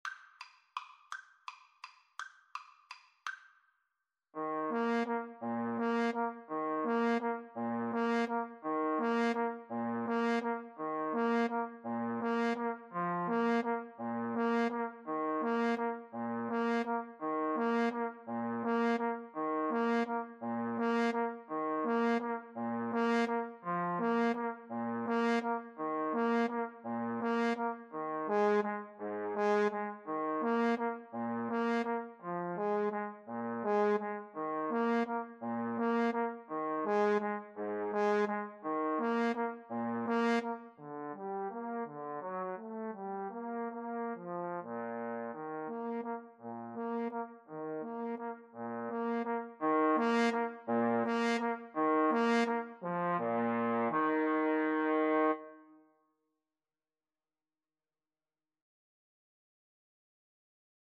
3/4 (View more 3/4 Music)
Tempo di valse =168
Classical (View more Classical Trumpet-Trombone Duet Music)